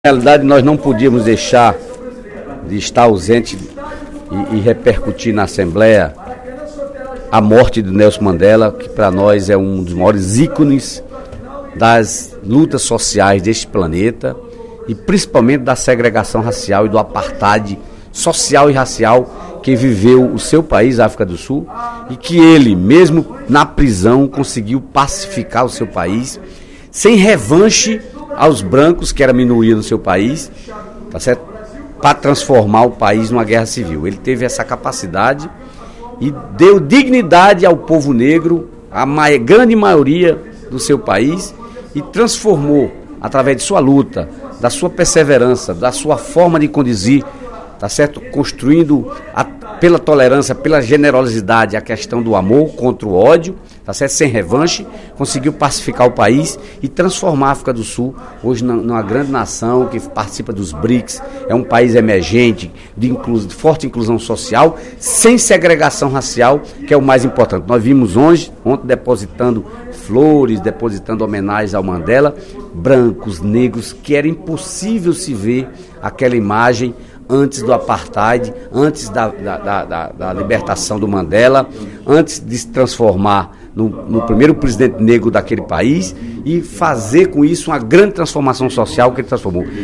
No primeiro expediente da sessão plenária desta sexta-feira (06/12), o deputado Dedé Teixeira (PT) lamentou o falecimento do ex-presidente da África do Sul Nelson Mandela, na última quinta-feira (05/12), aos 95 anos.
Em aparte, a deputada Eliane Novais (PSB) destacou que Mandela era um homem de paz e o maior militante da África do Sul.